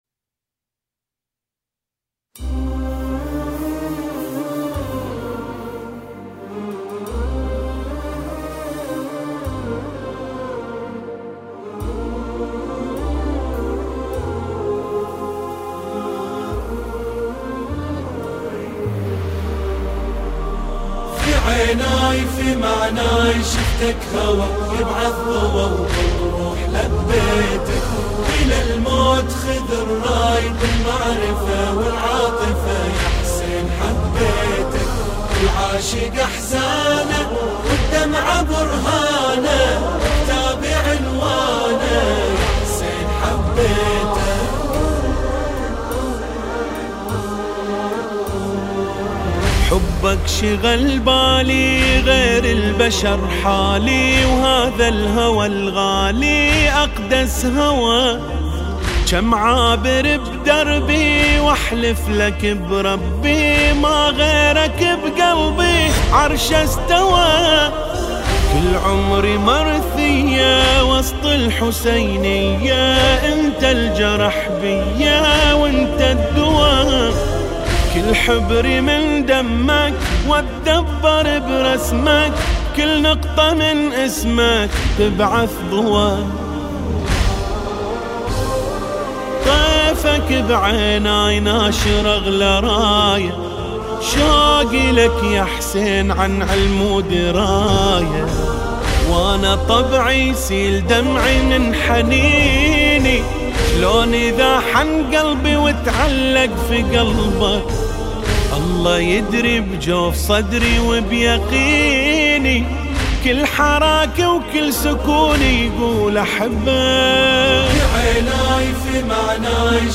لطميات